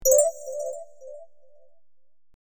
カードゲーム音候補